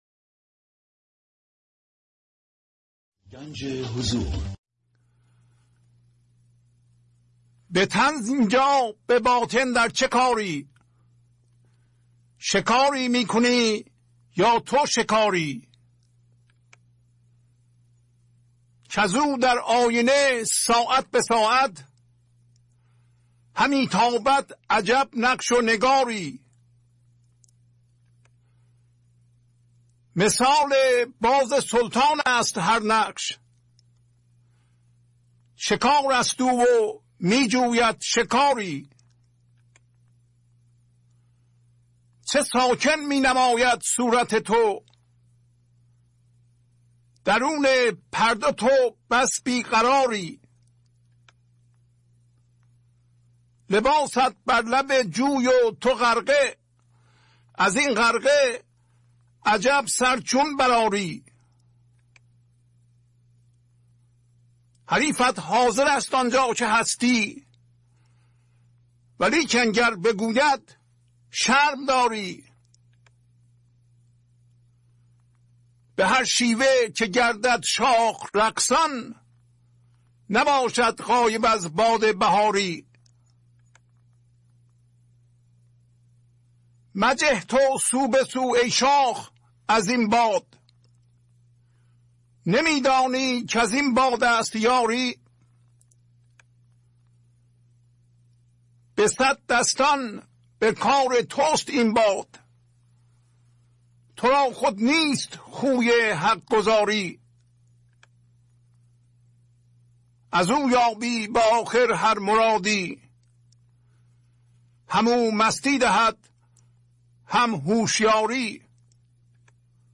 خوانش تمام ابیات این برنامه - فایل صوتی
917-Poems-Voice.mp3